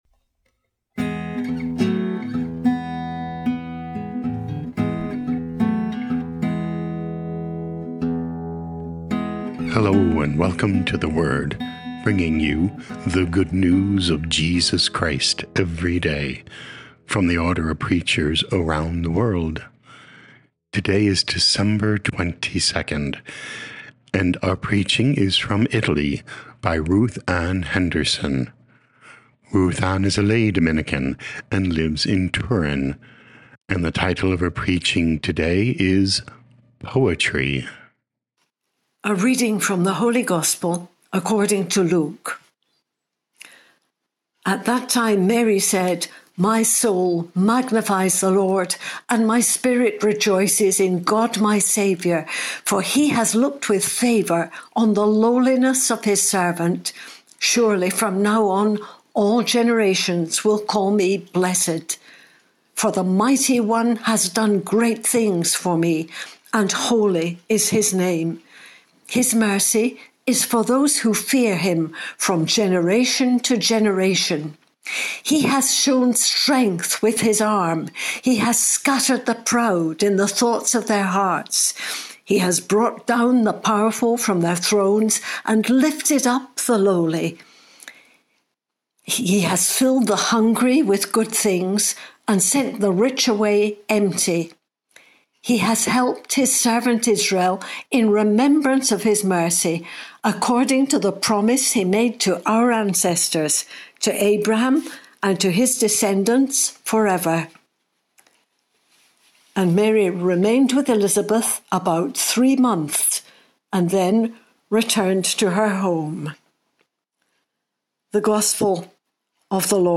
Podcast: Play in new window | Download For 22 December 2025, December 22, based on Luke 1:46-56, sent in from Turin, Italy.
Preaching